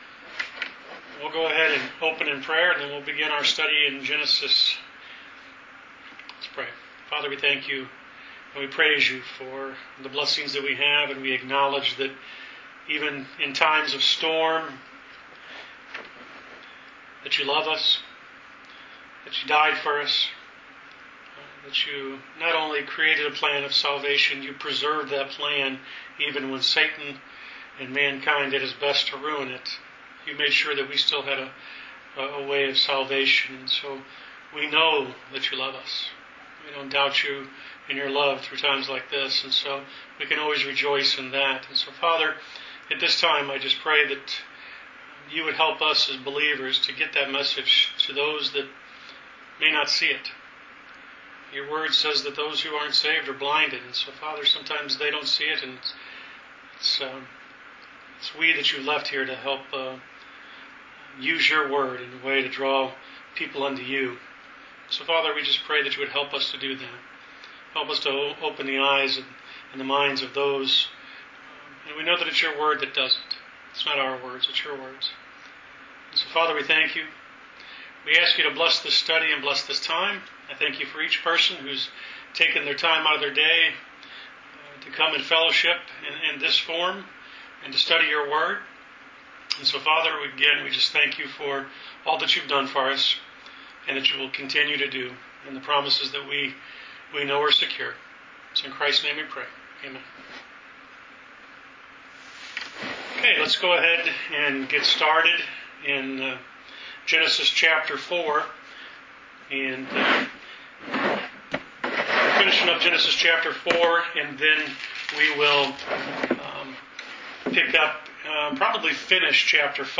Bible Study: Genesis Ch 5